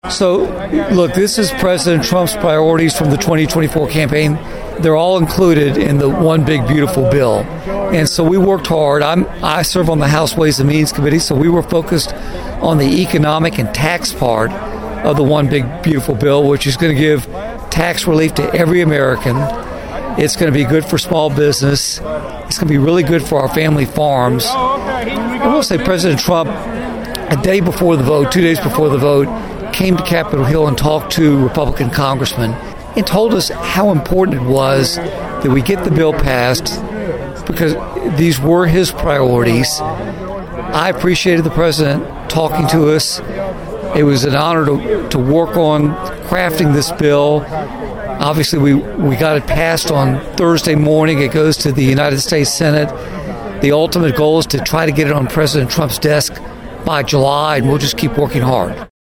During a stop last week in Martin, Congressman Kustoff told Thunderbolt News about the benefits of the bill.(AUDIO)